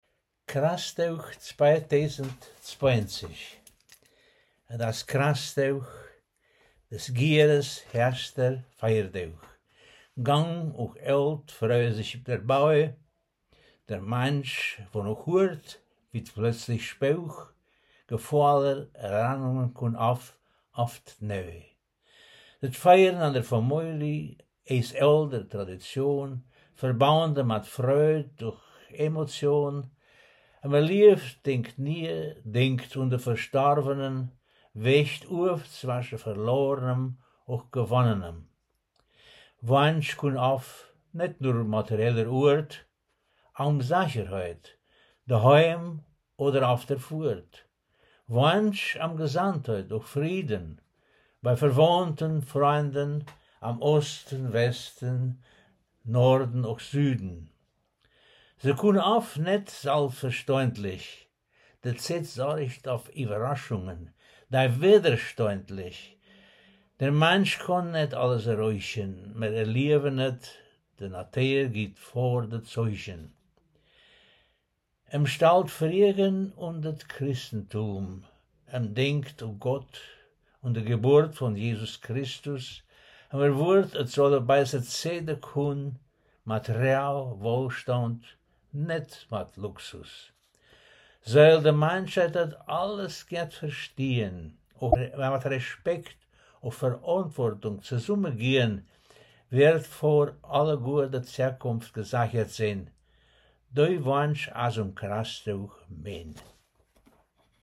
Ortsmundart: Zeiden